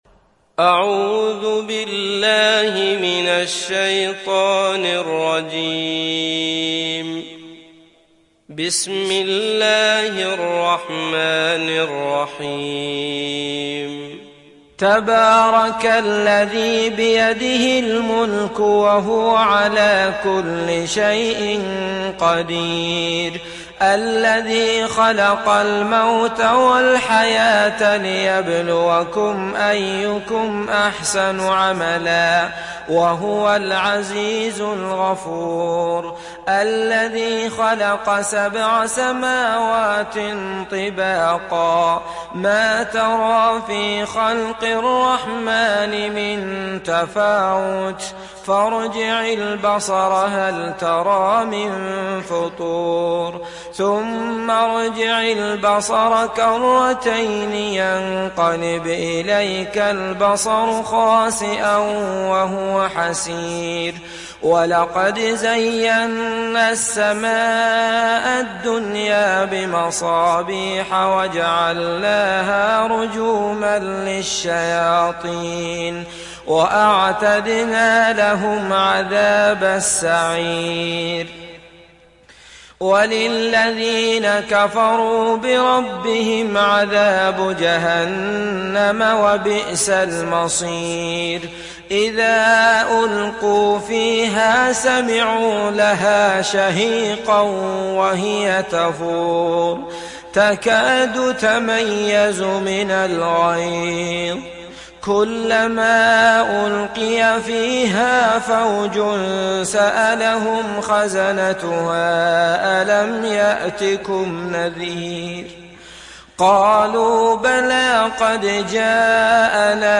Mülk Suresi İndir mp3 Abdullah Al Matrood Riwayat Hafs an Asim, Kurani indirin ve mp3 tam doğrudan bağlantılar dinle